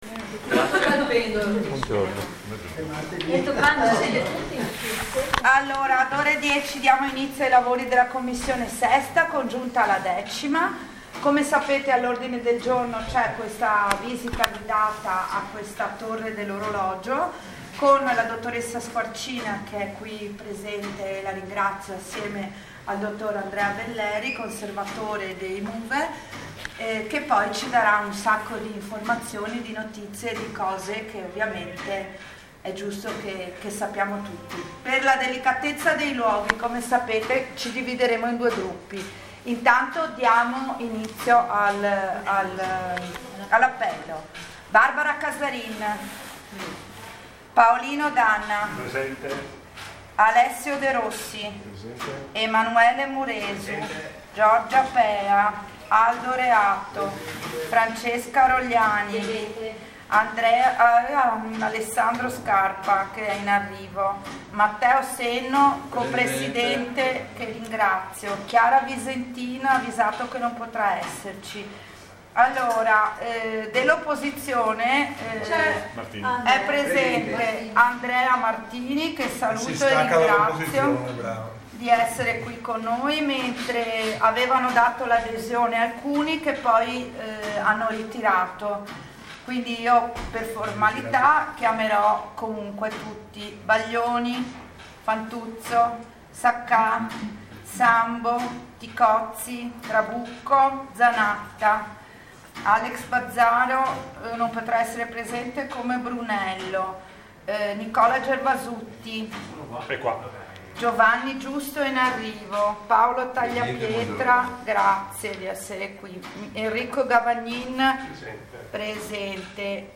Sopralluogo presso La Torre dell'Orologio